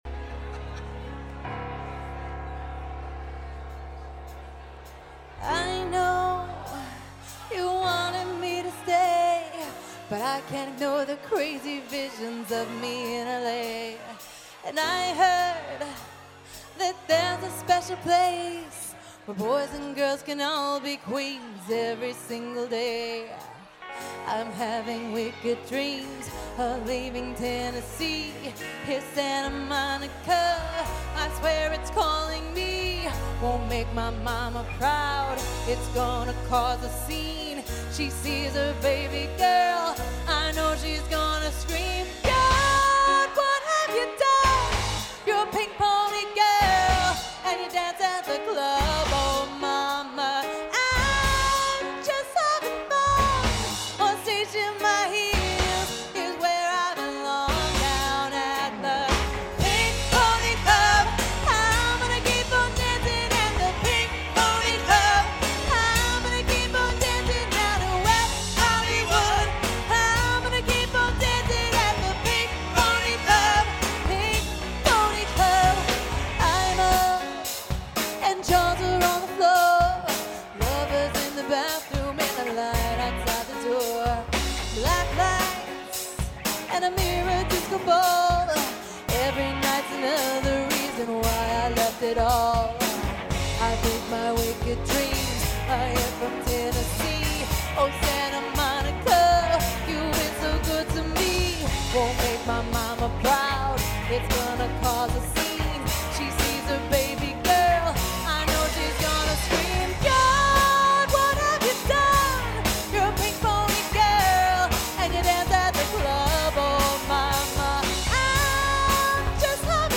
All Audio recordings are of the band performing live